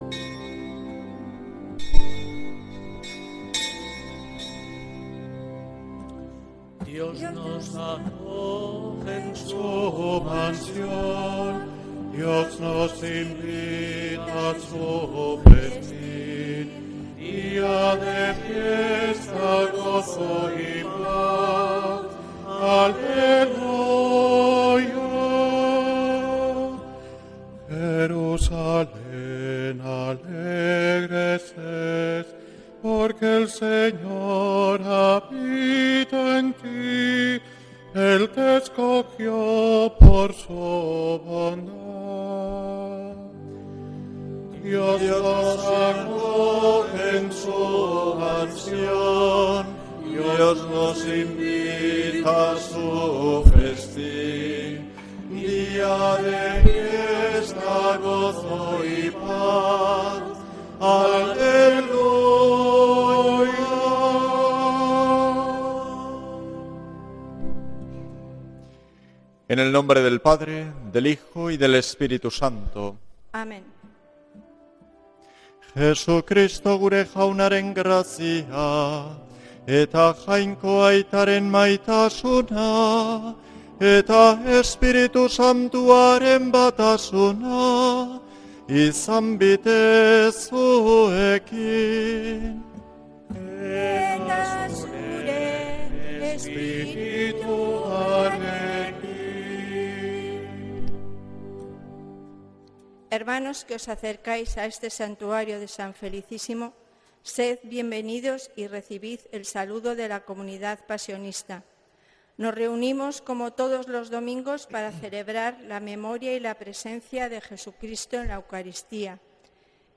Santa Misa desde San Felicísimo en Deusto, domingo 21 de septiembre de 2025